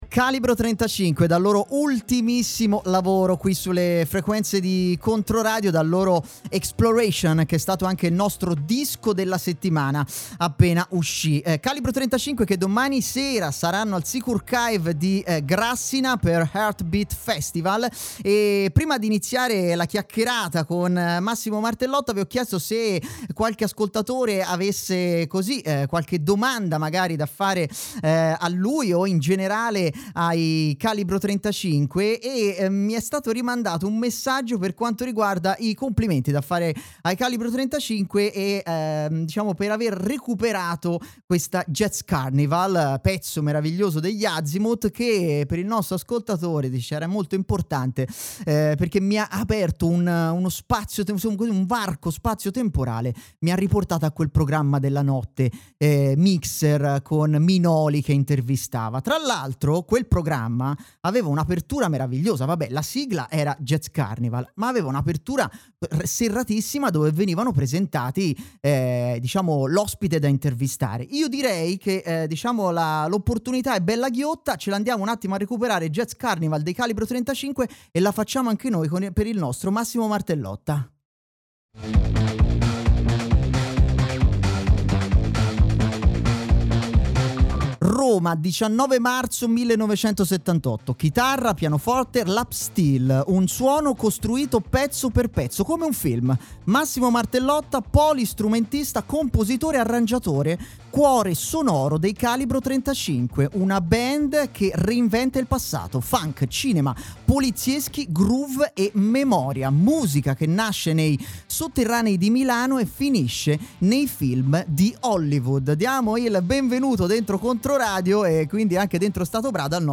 🎧 Calibro 35: Jazzploitation! Ascolta l’intervista